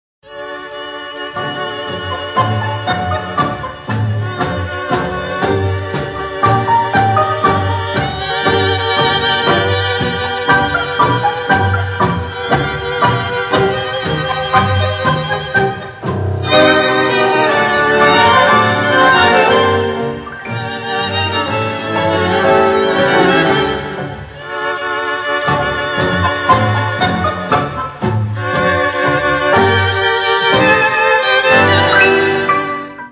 Klassische Tangos